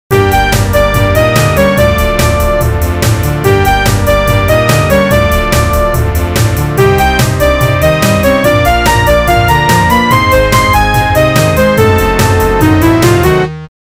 A loop that I created during instrument design.